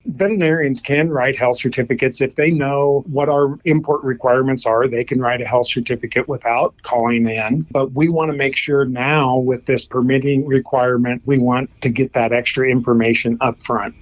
Audio with Roger Dudley, Nebraska State Veterinarian